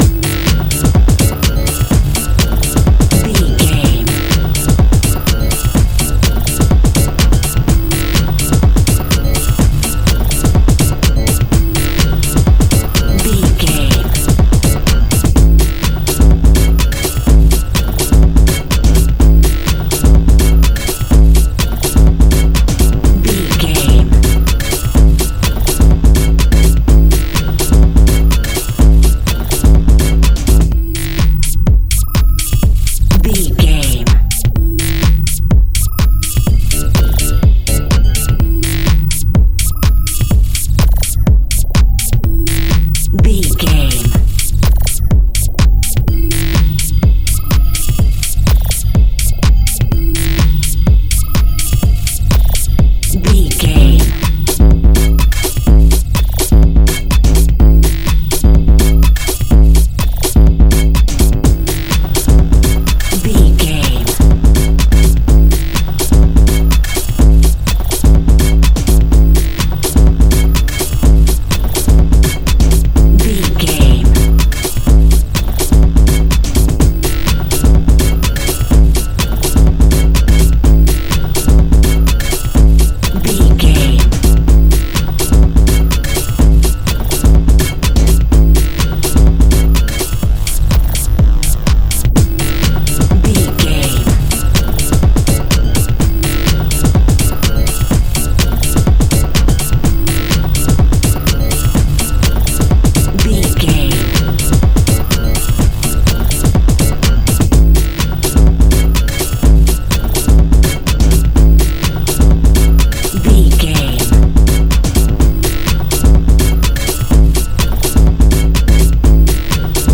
Epic / Action
Fast paced
Aeolian/Minor
Fast
groovy
uplifting
futuristic
driving
energetic
drums
synthesiser
drum machine
techno
trance
glitch
electronic music